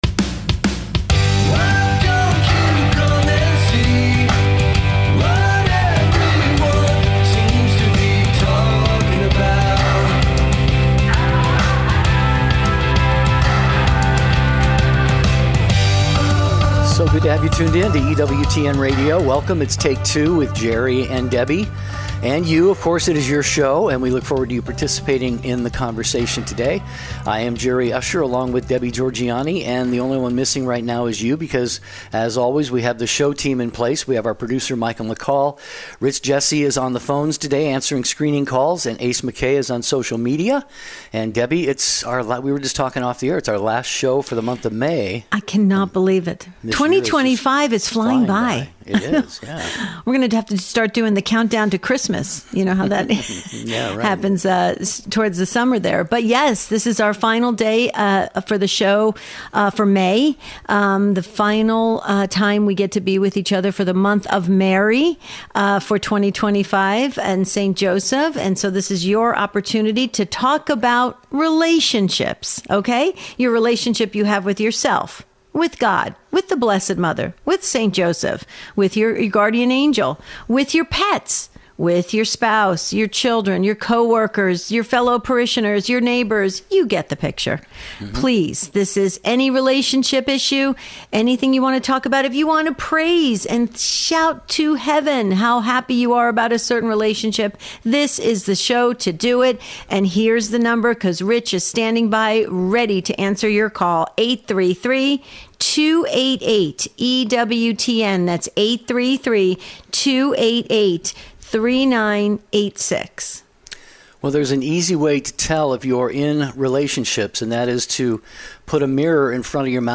All About Relationships. Unscripted.